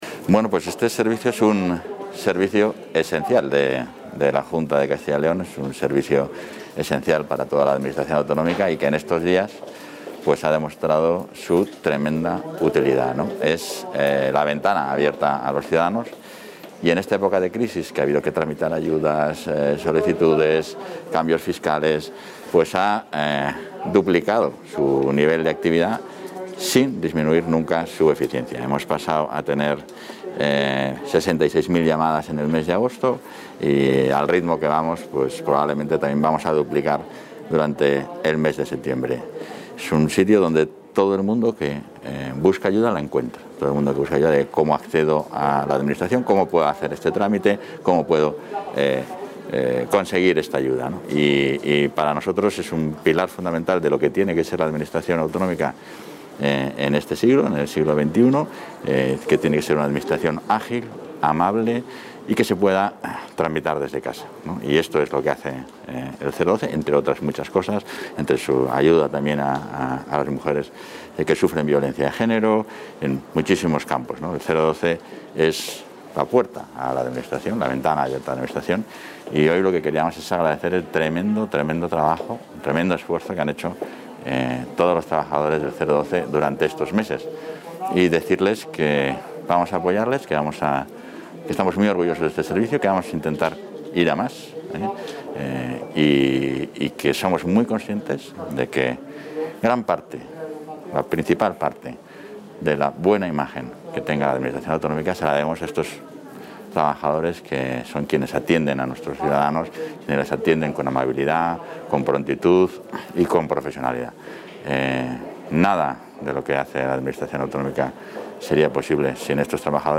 Intervención del vicepresidente y portavoz.